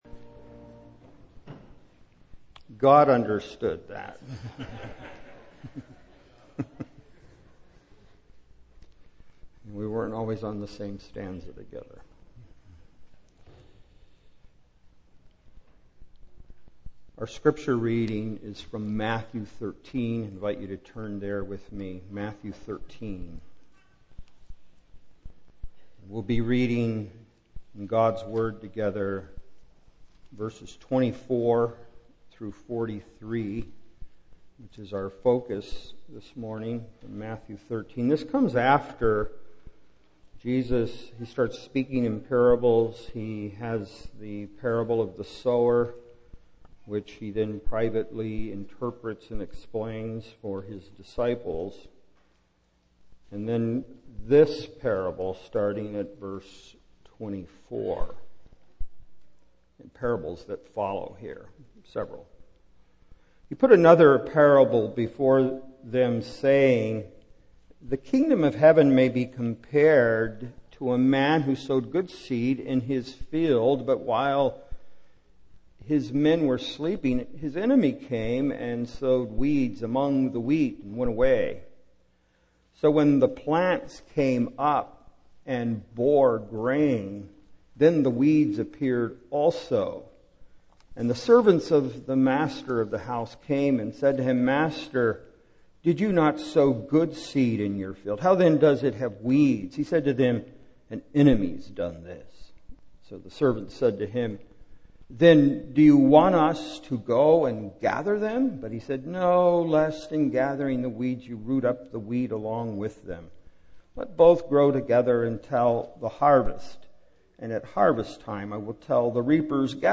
Series: Single Sermons
Service Type: Morning